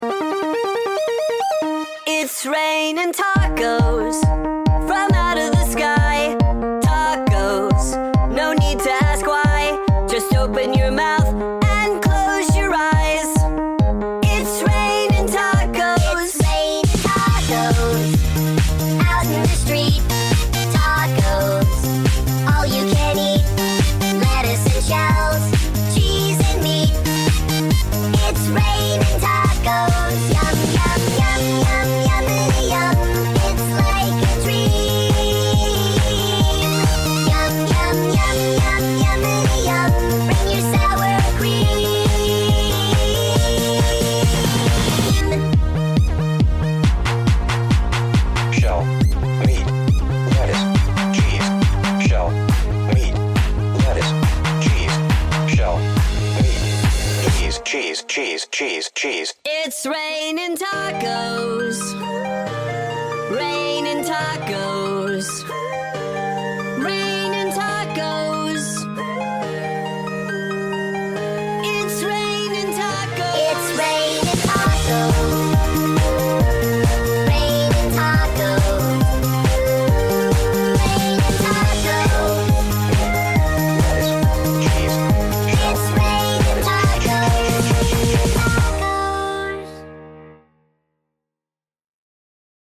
chaotic, catchy joy
MemeMusic